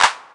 cch_03_clap_one_shot_high_classic_base.wav